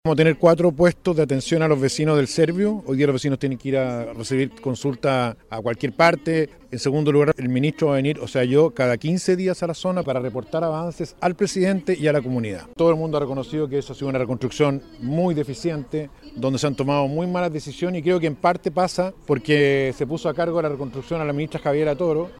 Sus declaraciones se enmarcan en una misa por el aniversario de Penco, hasta donde llegó el futuro secretario de Estado.